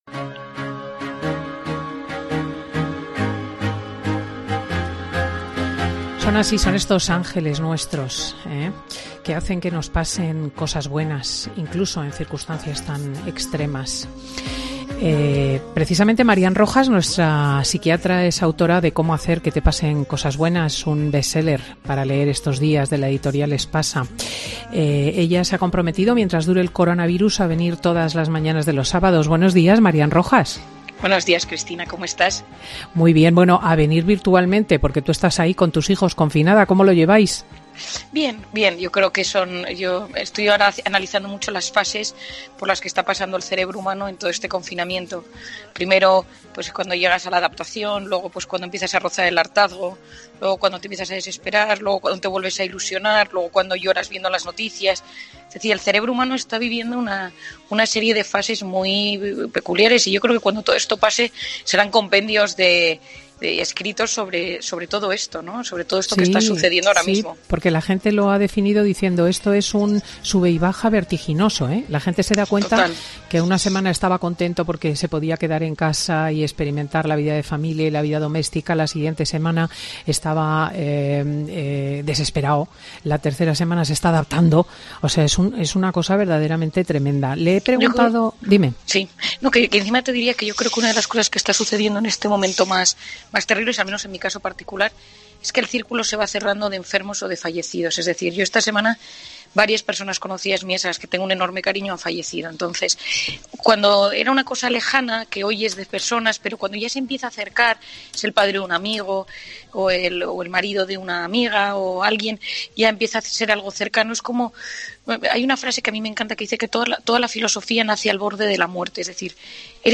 La psiquiatra visita todos los sábados Fin de Semana para atender consultas de los oyentes